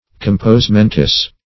Compos-mentis \Com"pos-men"tis\, n.